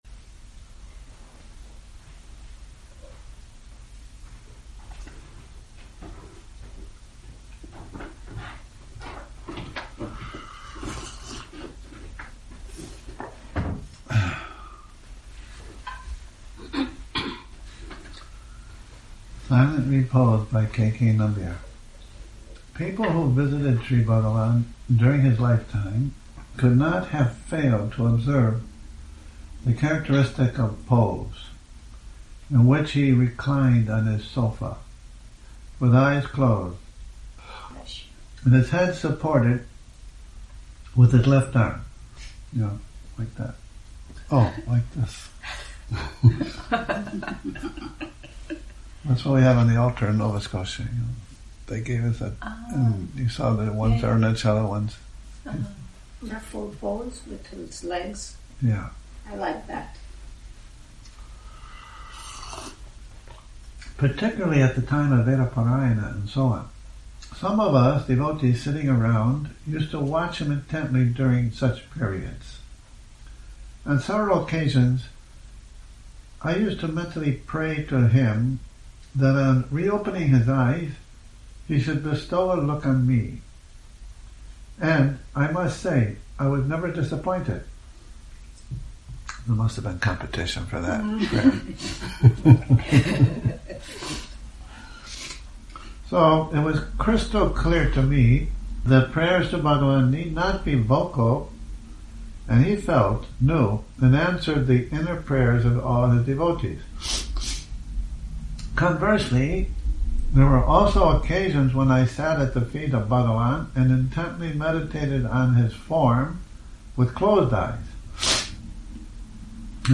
Morning Reading, 06 Nov 2019